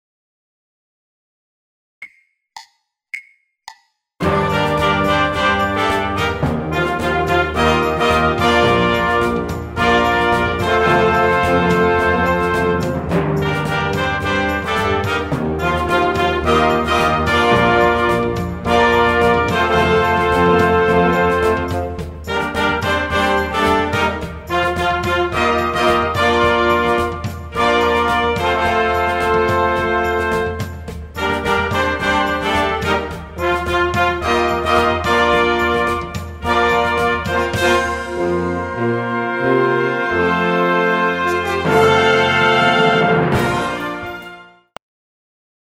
Originaltempo 108 bpm,